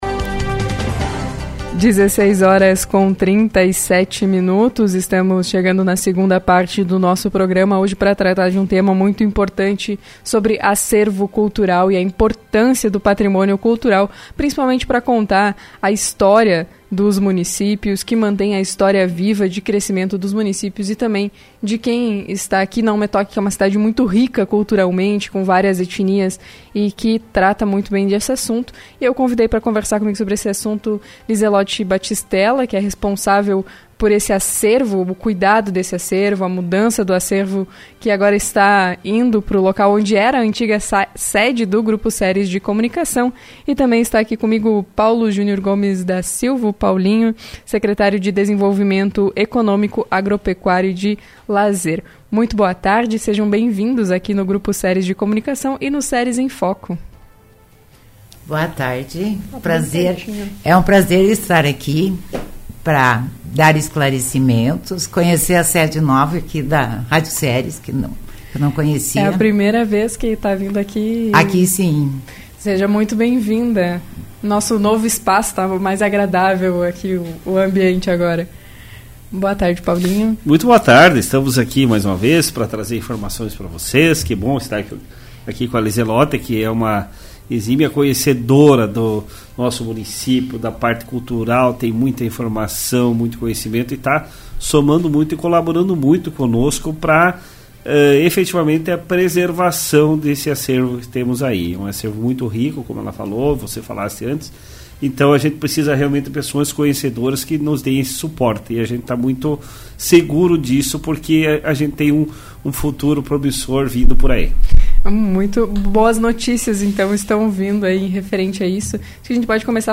Em uma entrevista ao Grupo Ceres